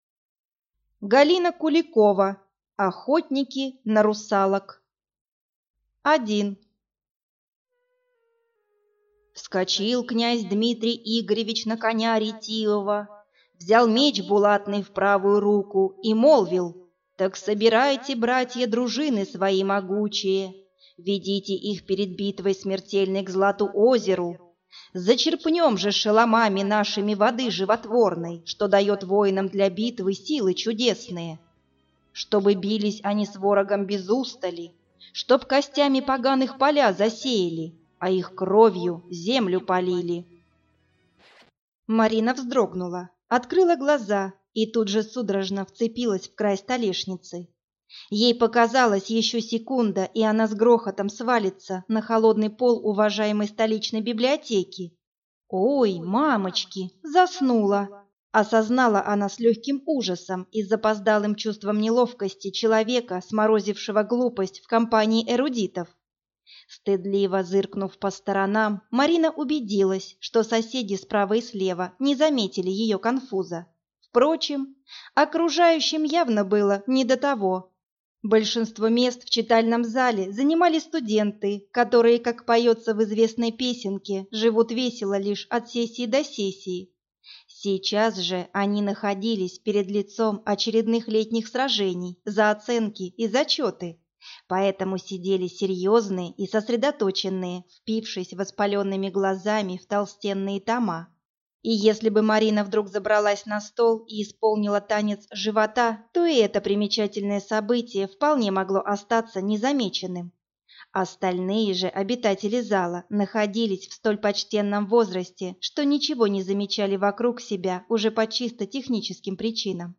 Аудиокнига Охотники на русалок | Библиотека аудиокниг
Прослушать и бесплатно скачать фрагмент аудиокниги